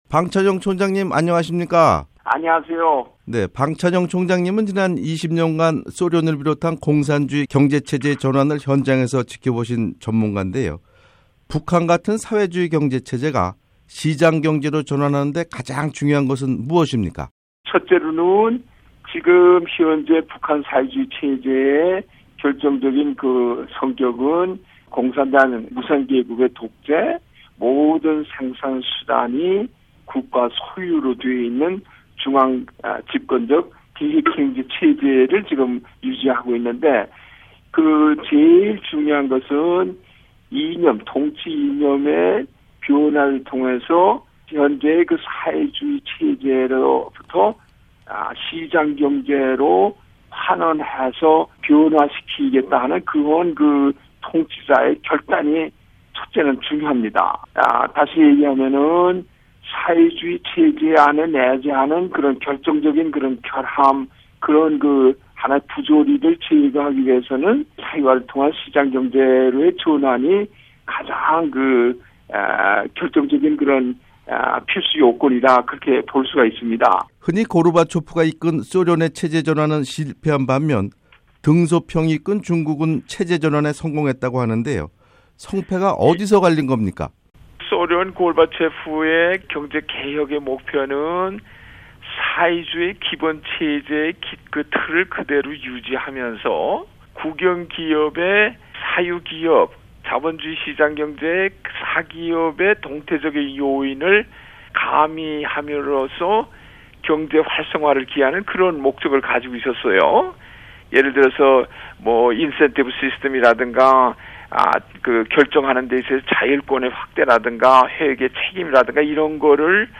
[기획 인터뷰]